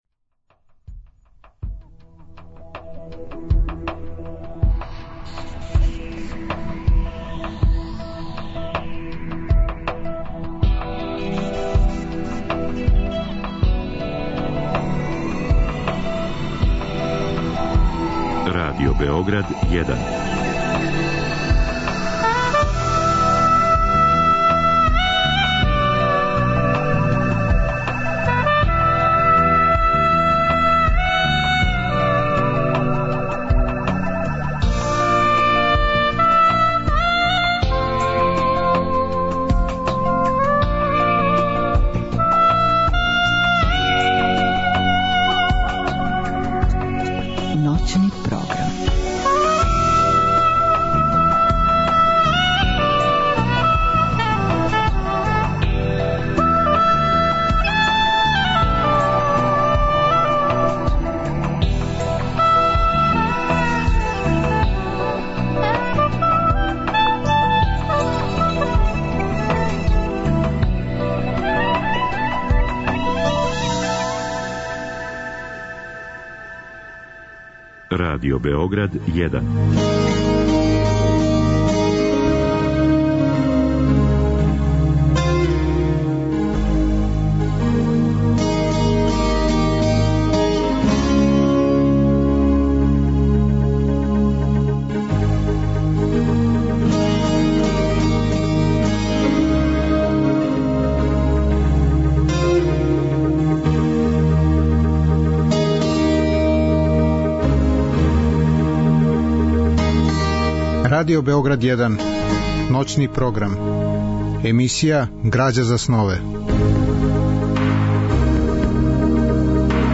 Разговор и добра музика требало би да кроз ову емисију и сами постану грађа за снове.
У другом делу емисије, од два до четири часa ујутро, слушаћемо одабране делове из радио-драме Ноћ и магла Данила Киша.